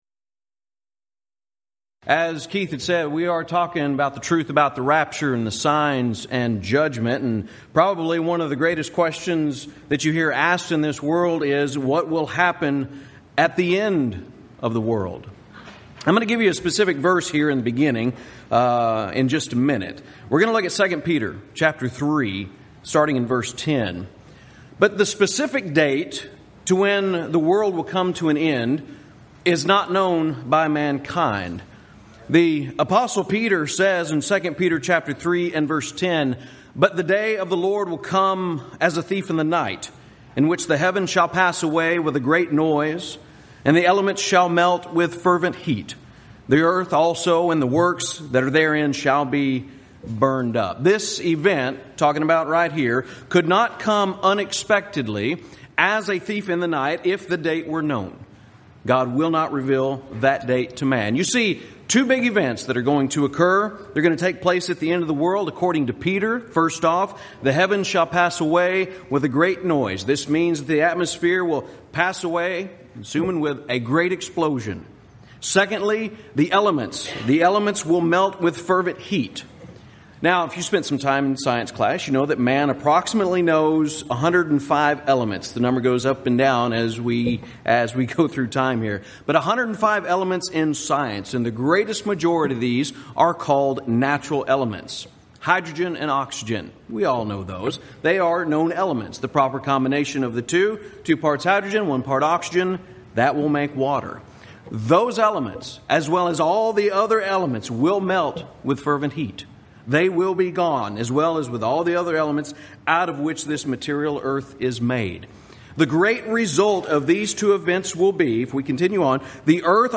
Event: 2015 South Texas Lectures
lecture